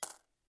coin_coin_11.ogg